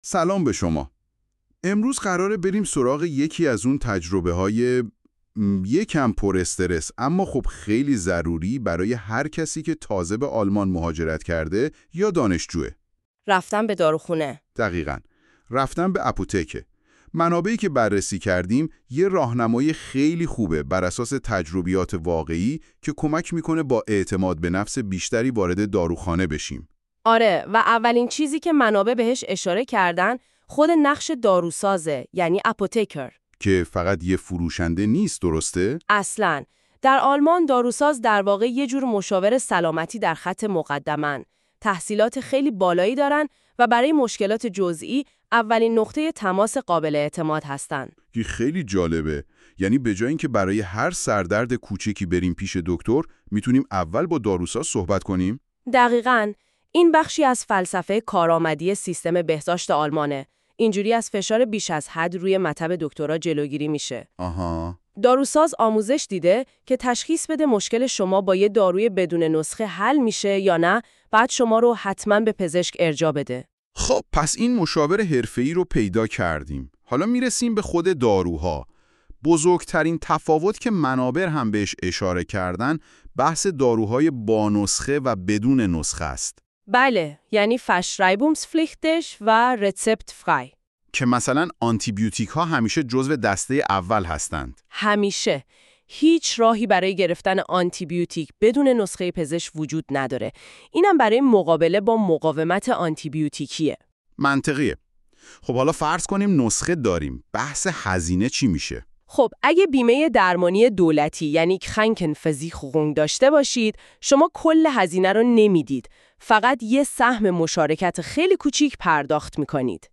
German-conversation-at-a-pharmacy.mp3